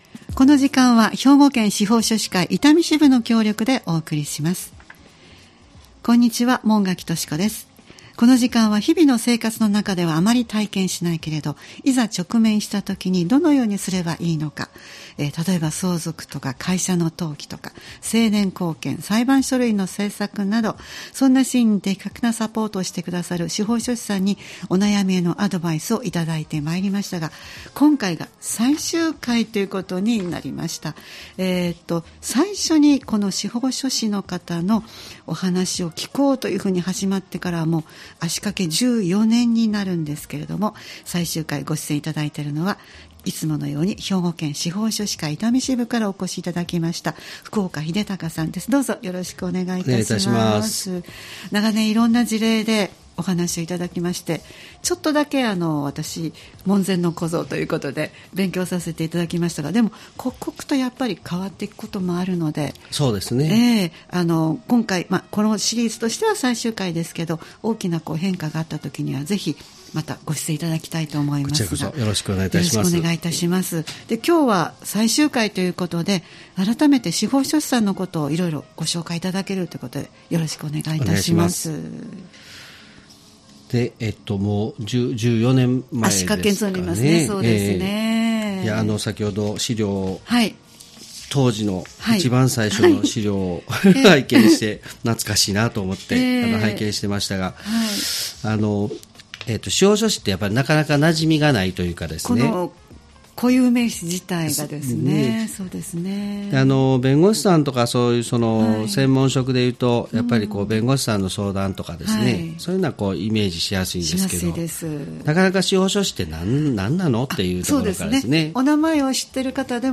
毎回スタジオに司法書士の方をお迎えして、相続・登記・成年後見・裁判書類の作成などのアドバイスをいただいています。